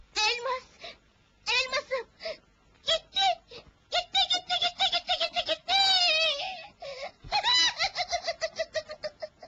adile-nasit-gulusu-part.mp3